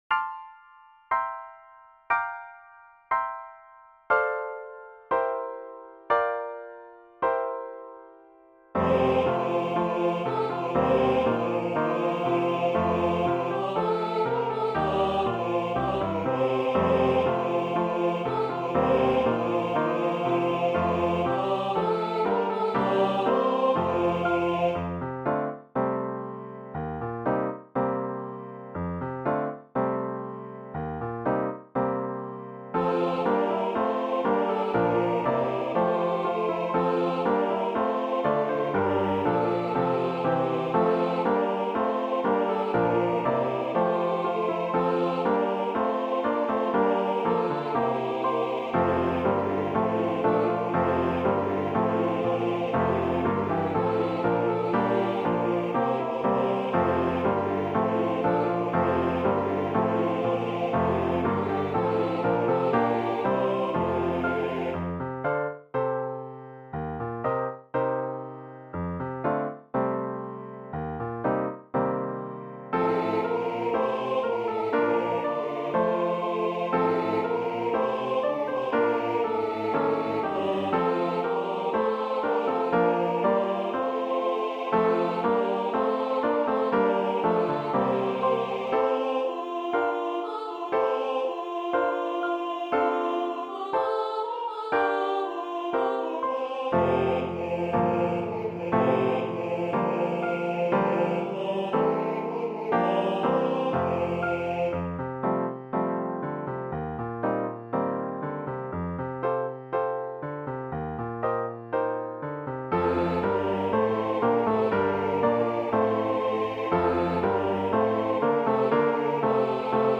This joyous Christmas hymn is included in Hymns for Home and Church. This French melody was published in 1863.
Voicing/Instrumentation: SATB We also have other 10 arrangements of " He is Born, the Divine Christ Child/Il Est Ne Le Divin Enfant ".
A Cappella/Optional A Capella